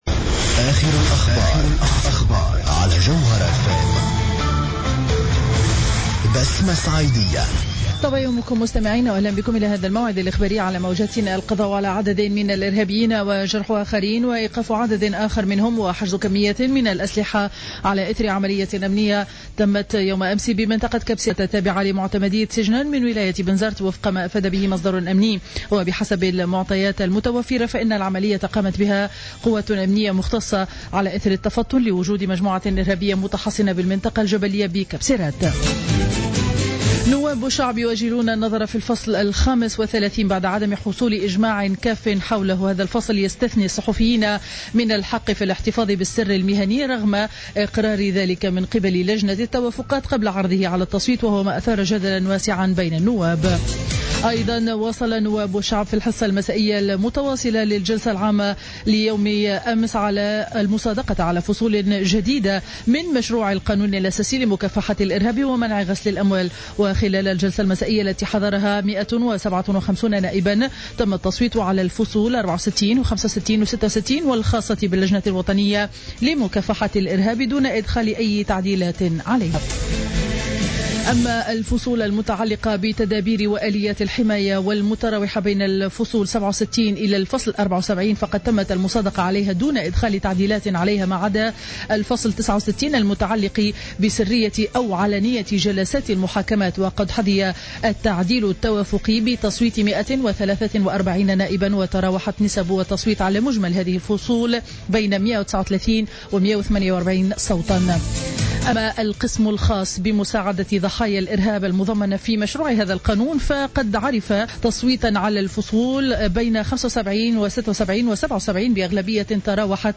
نشرة أخبار السابعة صباحا ليوم الجمعة 24 جويلية 2015